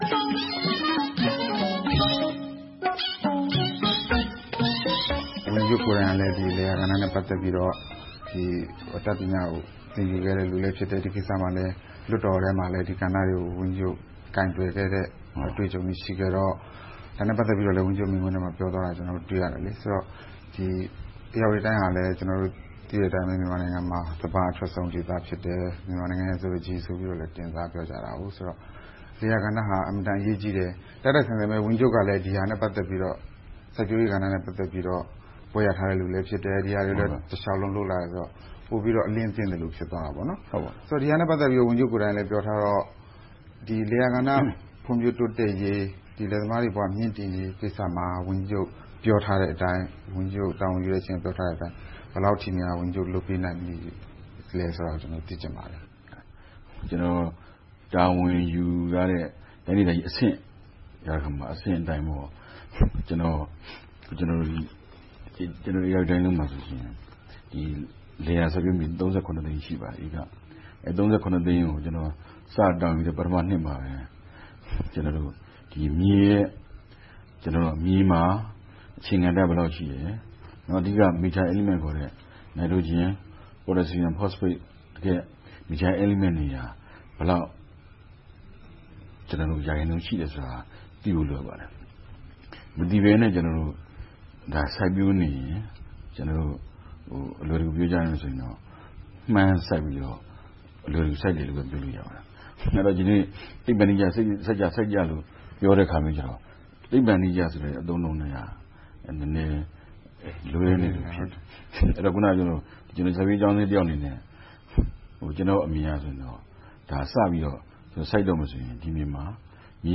ဧရာဝတီတိုင်းဝန်ကြီးချုပ်နဲ့ တွေ့ဆုံမေးမြန်းခန်း (ပထမပိုင်း)
ဇန်နဝါရီ ၂၆၊ ၂၀၂၀ - ဧရာဝတီတိုင်းဒေသကြီးအတွင်းက တောင်သူတွေ ဝင်ငွေတိုးအောင်နဲ့ လယ်ယာကဏ္ဍ တိုးတက်ဖွံ့ဖြိုးအောင် လုပ်ဆောင်မှုတွေ အခုချိန်မှာ ဘယ်လောက်ထိ အောင်မြင်ခရီးရောက်နေပါပြီလဲ။ တိုင်းဒေသကြီး အစိုးရရုံးစိုက်ရာ ပုသိမ်မြို့မှာ ဝန်ကြီးချုပ်ဦးလှမိုးအောင်ကို တွေ့ဆုံမေးမြန်းထားပါတယ်။